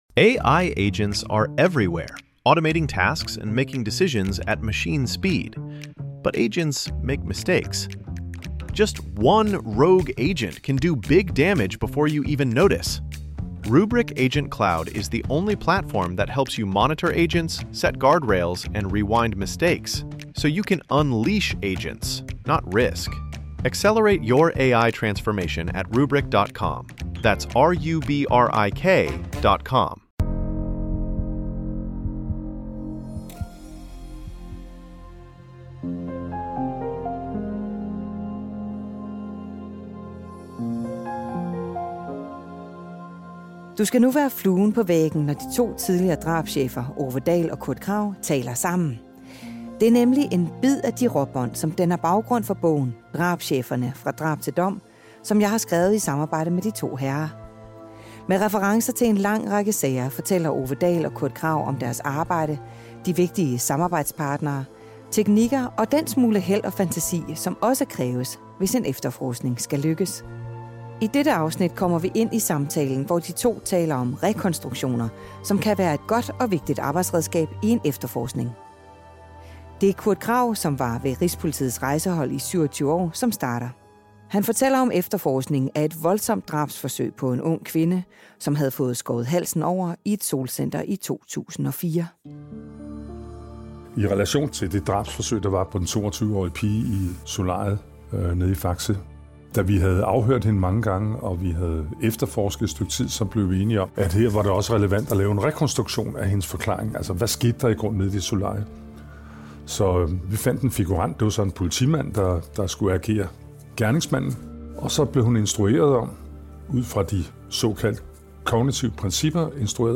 Kvinden havde fået skåret halsen over i et solcenter i 2004, og bagefter deltog hun i en rekonstruktion, som blev en øjenåbner for efterforskerne. Det hele er en bid af de råbånd, der danner baggrund for bogen Drabscheferne - fra drab til dom, som er udkommet på Politikens Forlag.